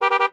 honk2.wav